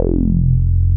RUBBER F2 P.wav